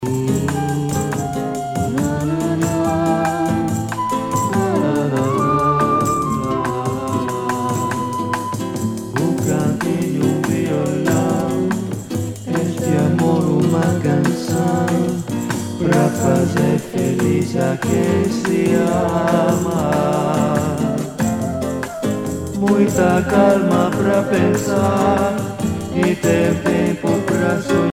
danse : bossa nova
Pièce musicale éditée